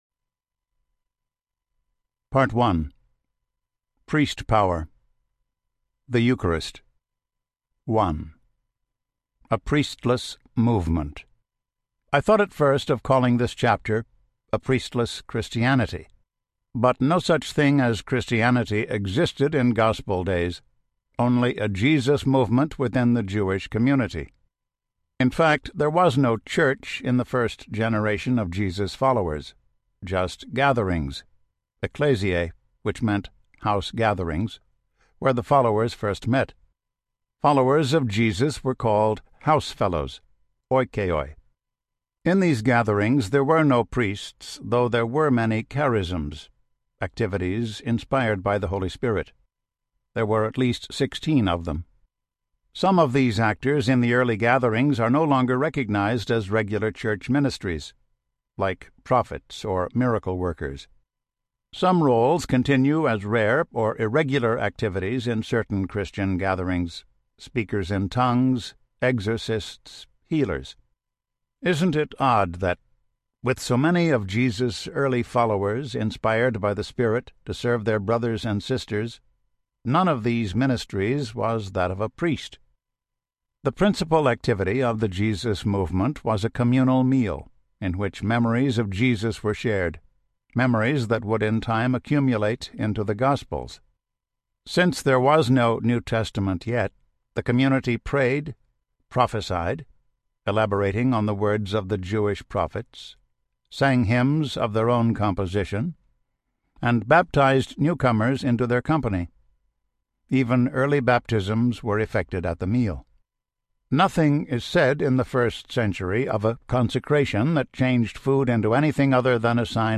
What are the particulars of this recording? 8.2 Hrs. – Unabridged